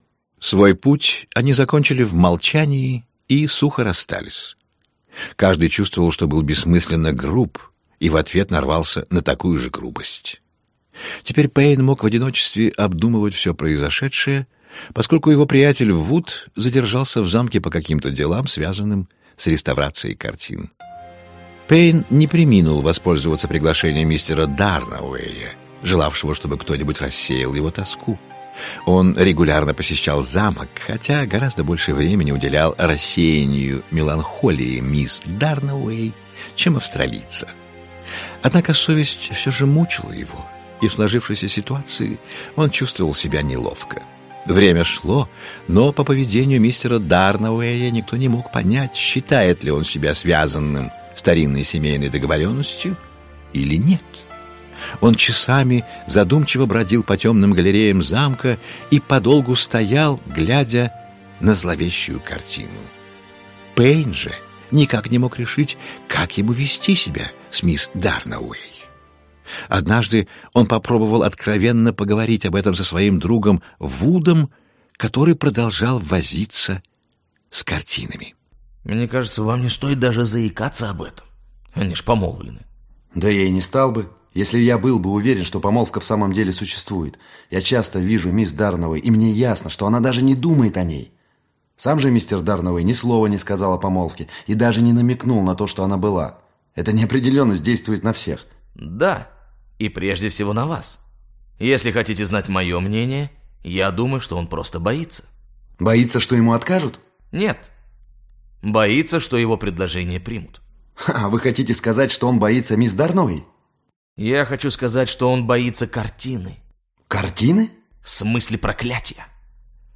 Аудиокнига Рок семьи Дарнауэй (спектакль) | Библиотека аудиокниг
Aудиокнига Рок семьи Дарнауэй (спектакль) Автор Гилберт Кит Честертон Читает аудиокнигу Виктор Раков.